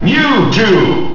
The announcer saying Mewtwo's name in Super Smash Bros. Melee.
Mewtwo_Announcer_SSBM.wav